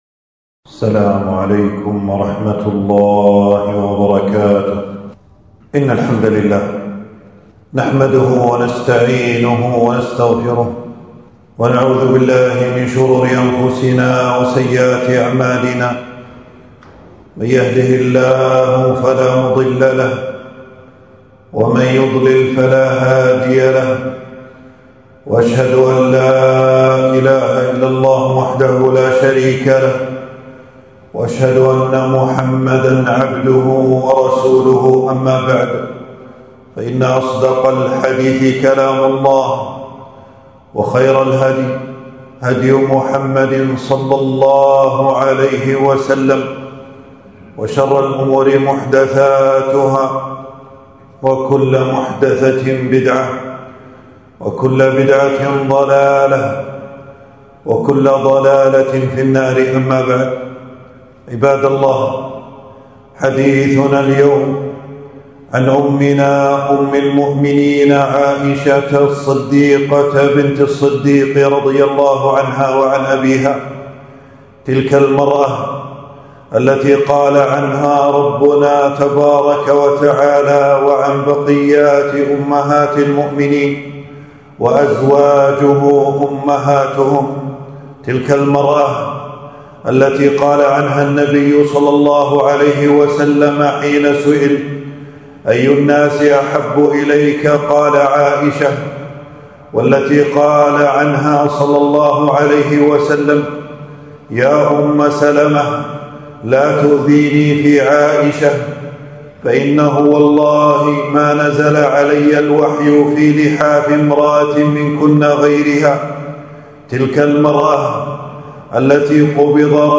خطبة - حادثة الأفك وفضل عائشة رضي الله عنها